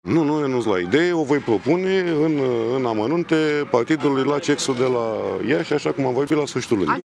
Premierul Mihai Tudose a declarat astăzi, după şedinţa CEx, că a lansat ideea restructurării Guvernului, dar va fi discutată, în detaliu, în şedinţa de la finalul lunii de la Iaşi.
Premierul Mihai Tudose: